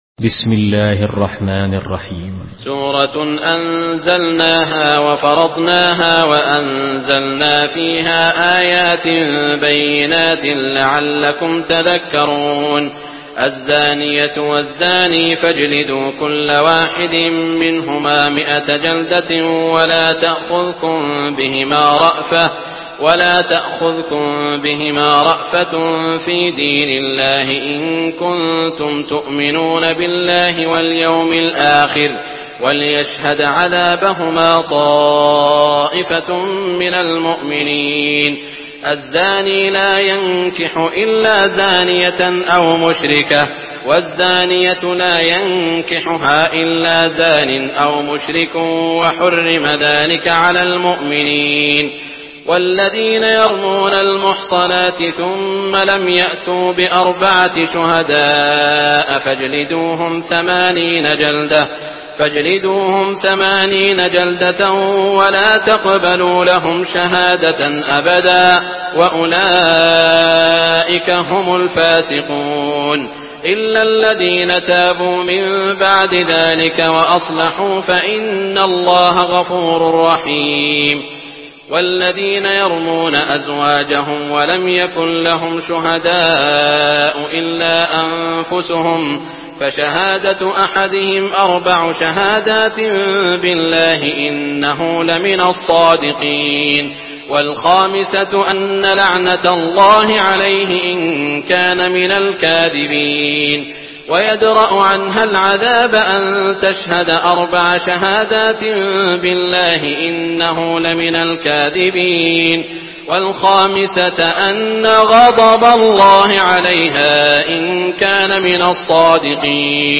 Surah An-Nur MP3 by Abdul Rahman Al Sudais in Hafs An Asim narration.
Murattal Hafs An Asim